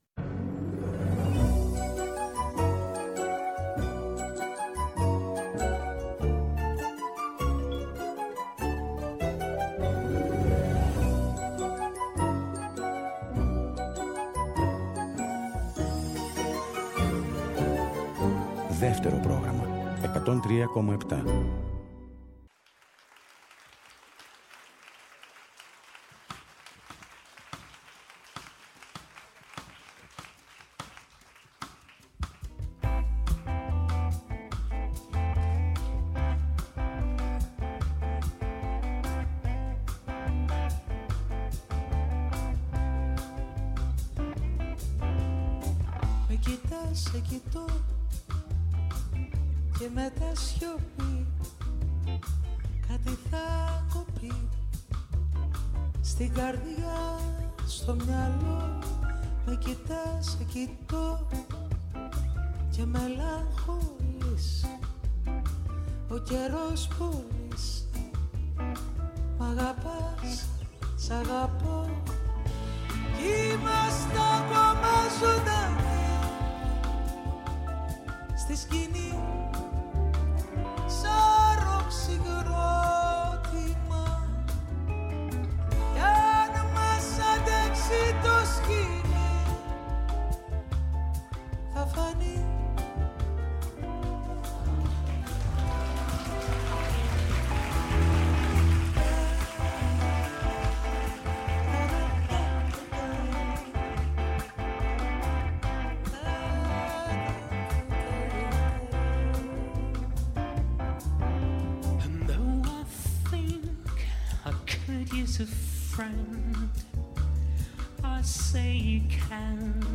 Στο “Μελωδικό Αντίδοτο” oι καινούριες μουσικές κάνουν παρέα με τις παλιές αγαπημένες σε μια ώρα ξεκούρασης καθώς επιστρέφουμε από μια κουραστική μέρα.